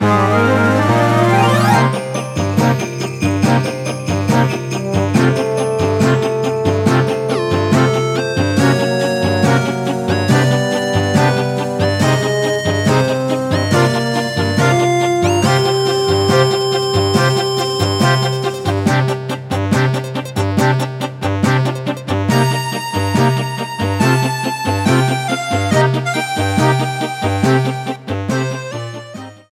Ripped from the game files
applied fade-out on last two seconds when needed